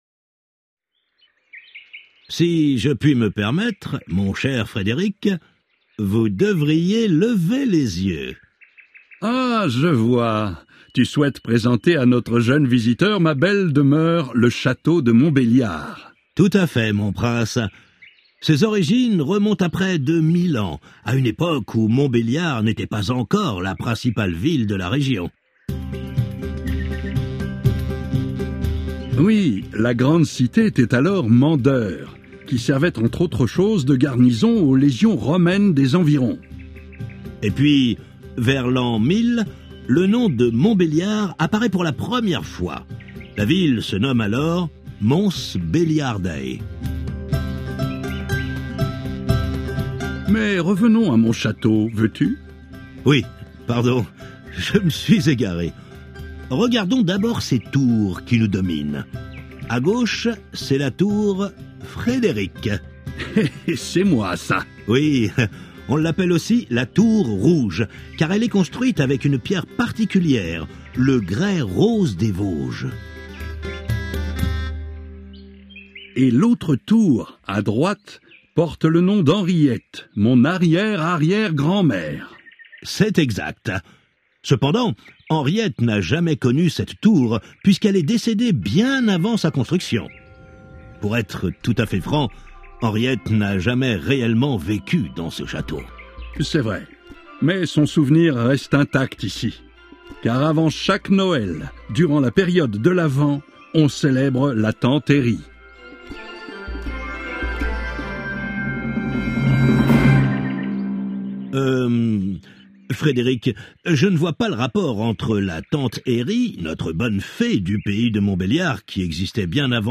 Il s’agit d’une discussion entre Frédéric 1er, duc de Wurtemberg et son architecte, Heinrich Schickhardt.